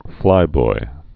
(flīboi)